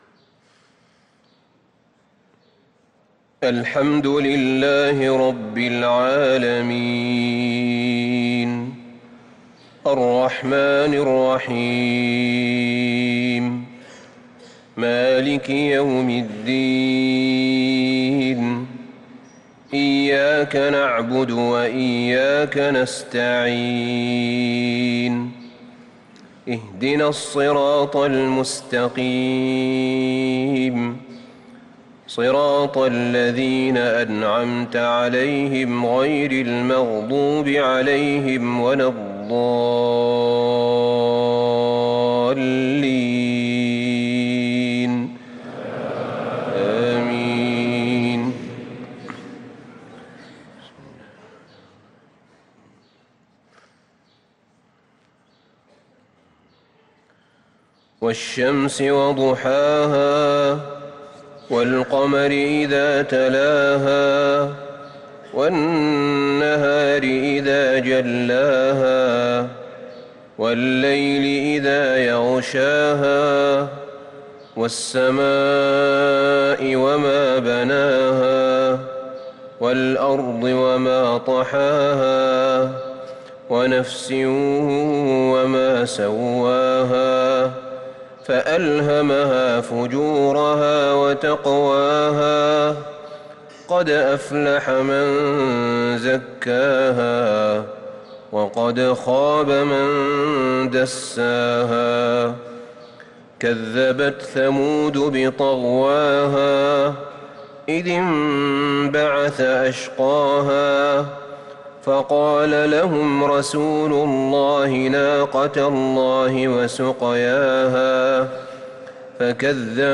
صلاة العشاء للقارئ أحمد بن طالب حميد 17 ربيع الآخر 1444 هـ